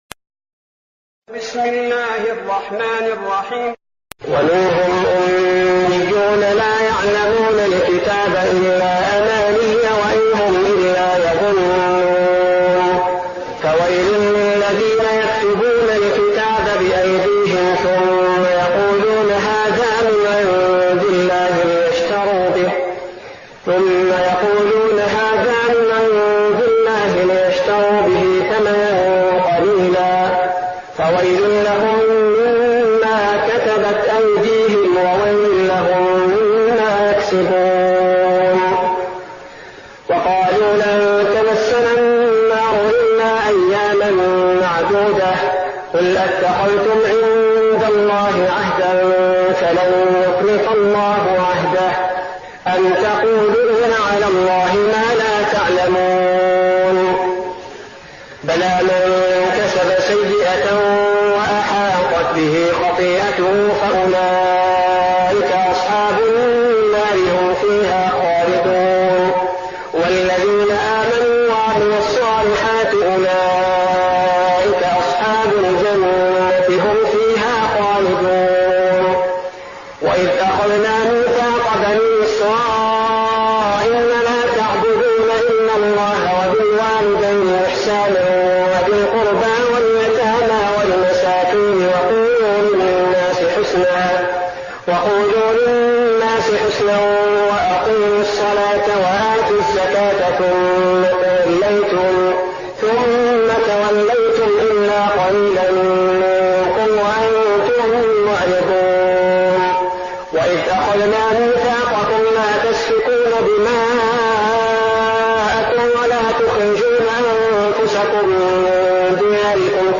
تهجد رمضان 1415هـ من سورة البقرة (78-141) Tahajjud night Ramadan 1415H from Surah Al-Baqara > تراويح الحرم النبوي عام 1415 🕌 > التراويح - تلاوات الحرمين